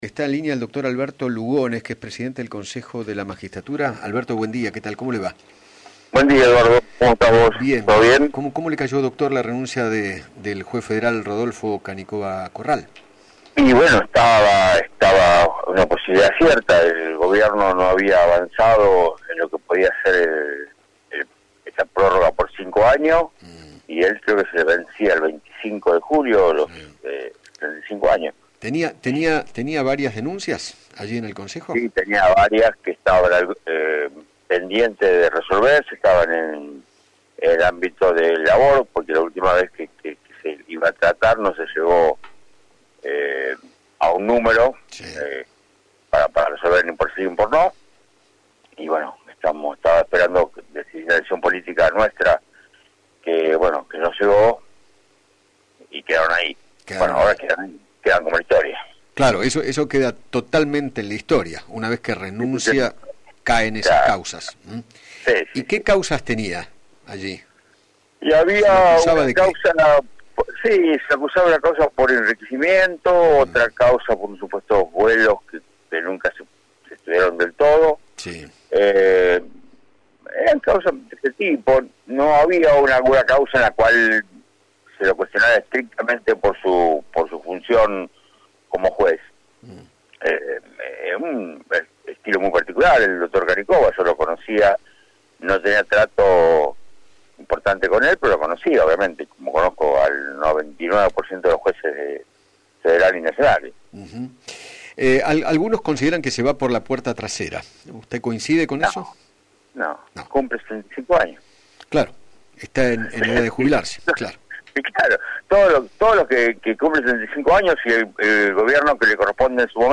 Alberto Lugones, presidente del Consejo de la Magistratura, dialogó con Eduardo Feinmann sobre la renuncia del juez federal Rodolfo Canicoba Corral.